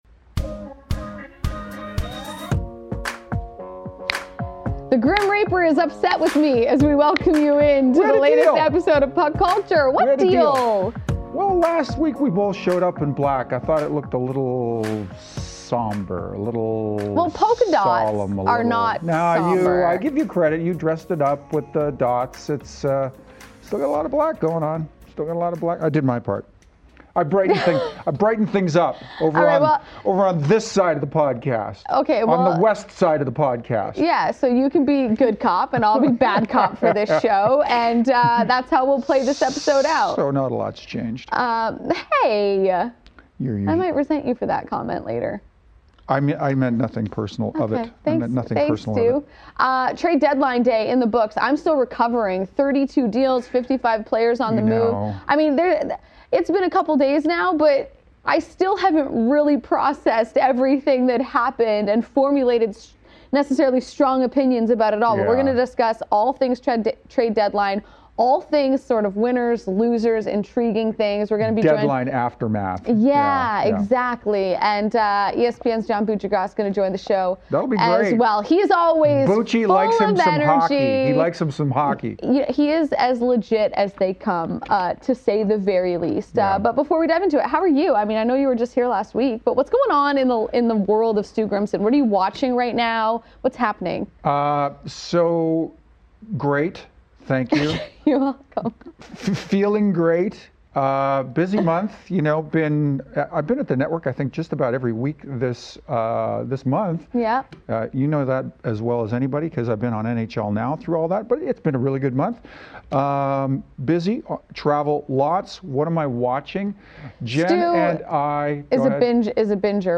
Jackie Redmond and Stu Grimson welcome ESPN's John Buccigross to the pod for an extended conversation on all the NHL Trade Deadline moves, John shares his thoughts on the Maple Leafs struggles, Joe Thornton staying put in San Jose, the EBUG rule, Ken Holland's plan in Edmonton...